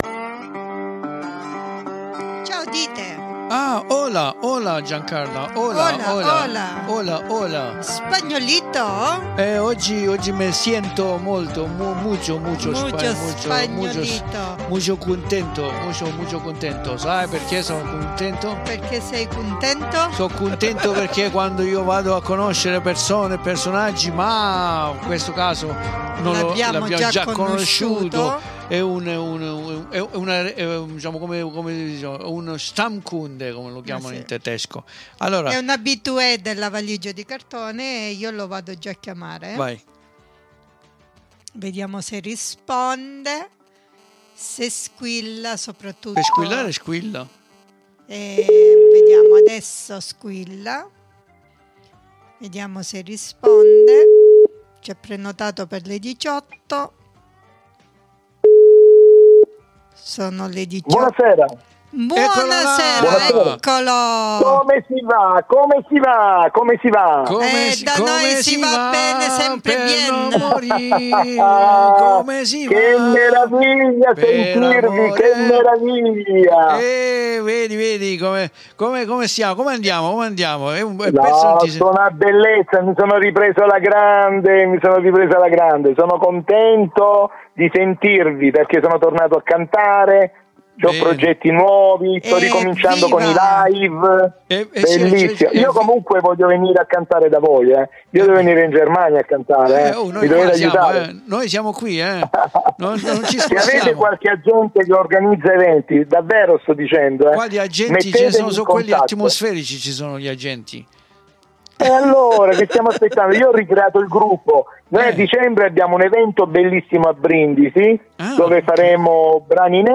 NON VI SPIFFERO ALTRO E VI INVITO AD ASCOLTARE LA SUA INTERVISTA CONDIVISA QUI IN DESCRIZIONE!